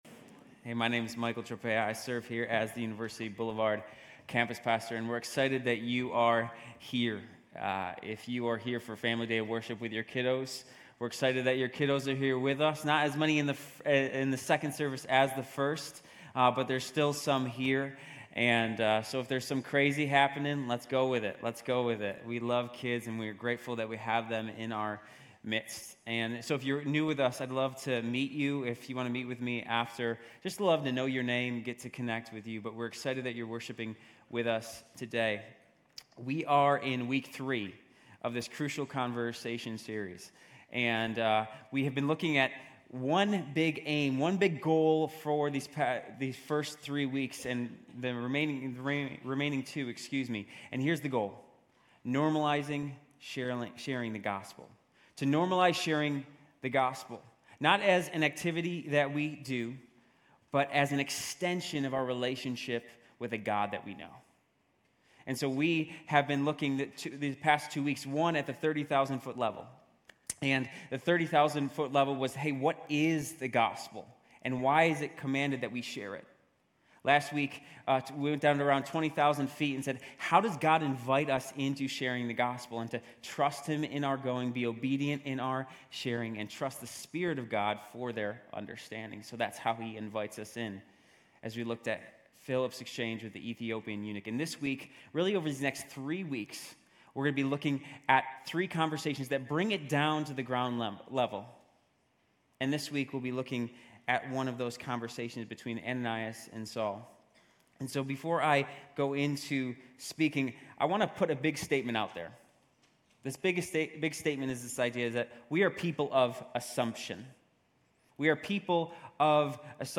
GCC-UB-October-29-Sermon.mp3